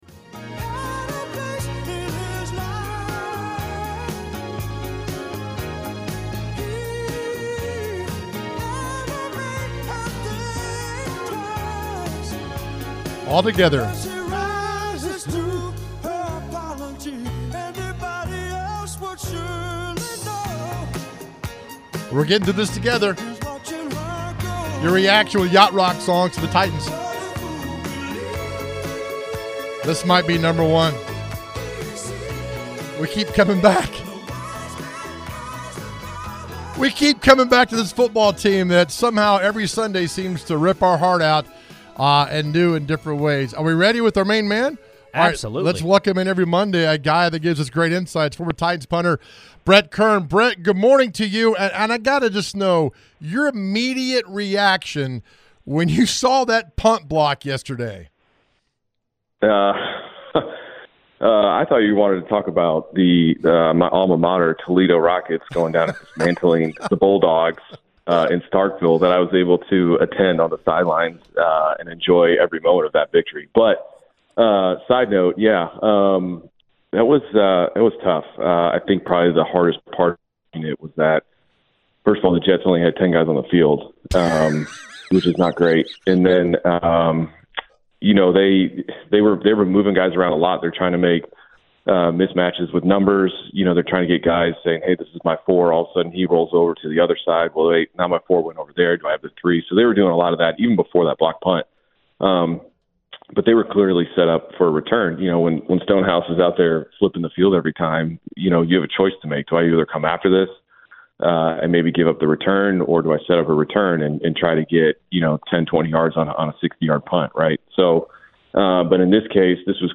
Brett Kern joins the show with the guys and talks about the situation with the Titans about the week 2 loss versus the Jets. He also mentions if it is time to move on from Will Levis or to stick with him through this process.